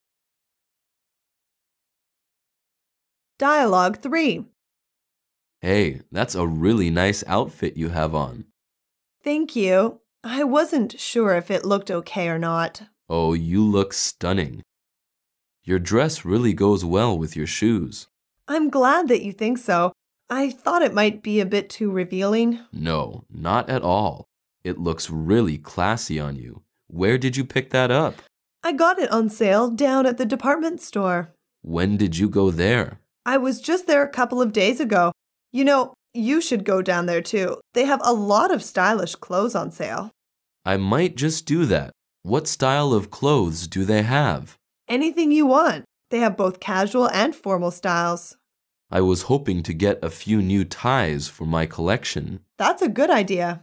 Dialouge 3